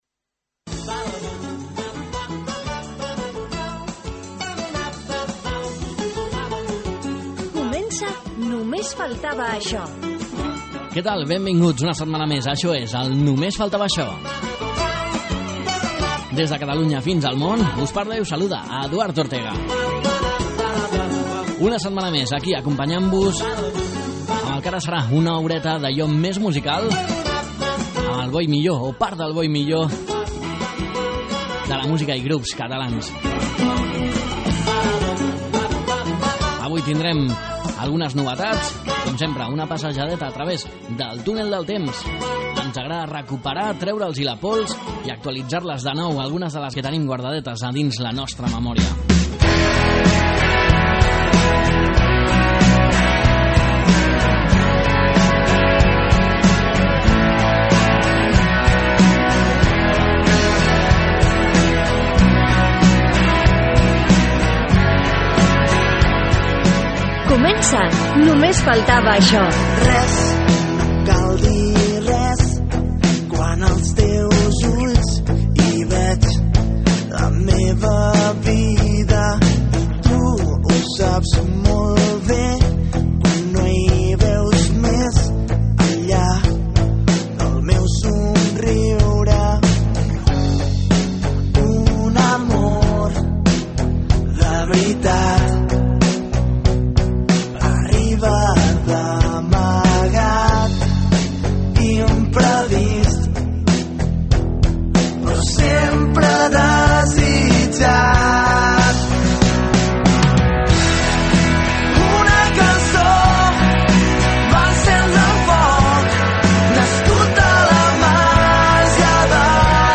Un programa de música en català que pots escoltar al 107.9fm